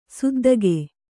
♪ suddage